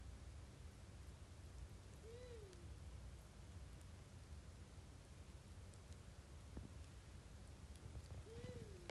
Birds -> Owls ->
Long-eared Owl, Asio otus
StatusVoice, calls heard